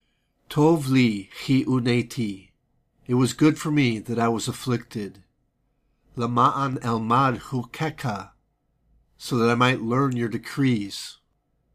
tohv'-lee · khee-oo·ney'·tee · le·ma'·an · el·mahd · choo·kei'·kha
Psalm 119:71 Hebrew Reading (click):